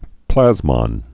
(plăzmŏn)